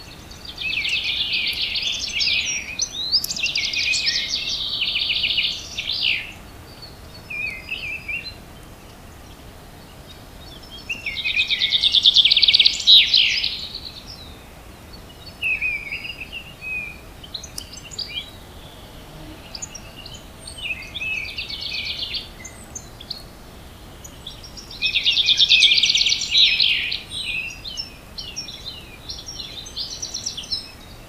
birdsongLoop_1.wav